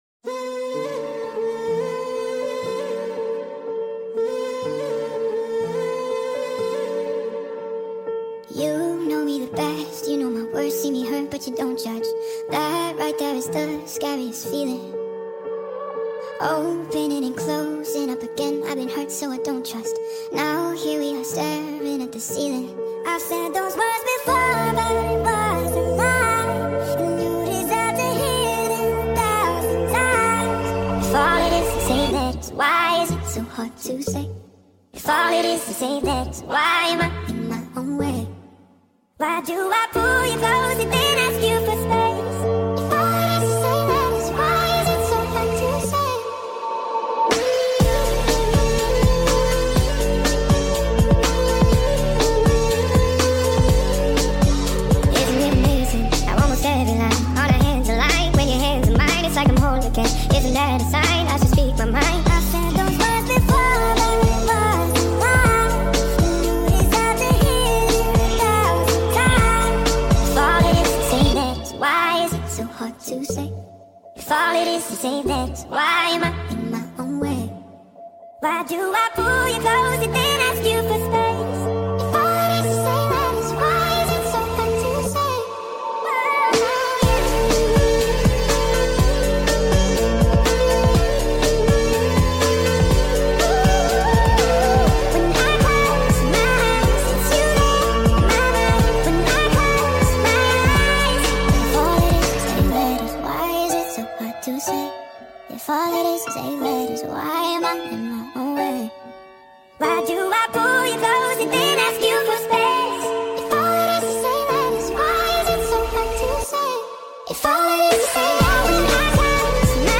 (Nightcore)